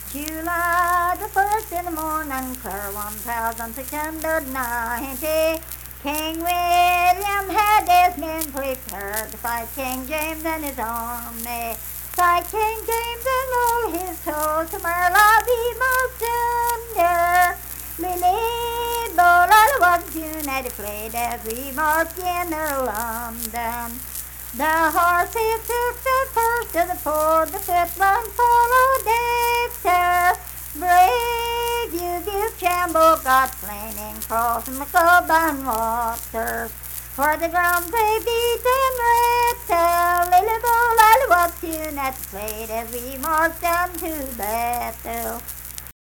Unaccompanied vocal music performance
Verse-refrain 3(4).
Voice (sung)